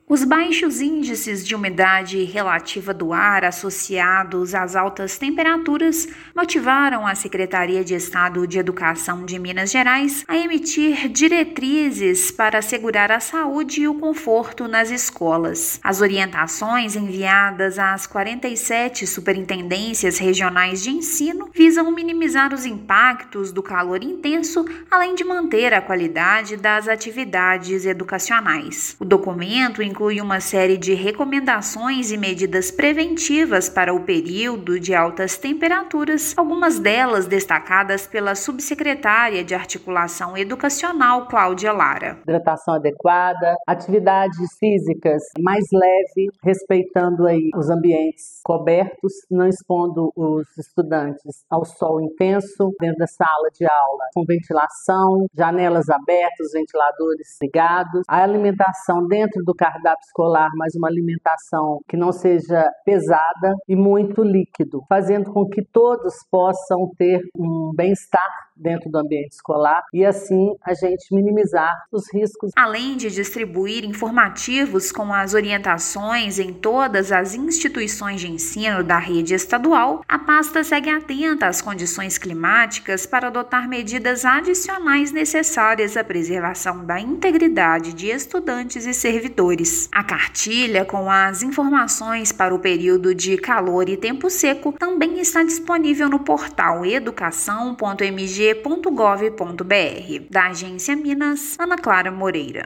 Recomendações incluem hidratação adequada, ventilação eficiente e vestimentas leves para garantir o bem-estar de estudantes e servidores. Ouça matéria de rádio.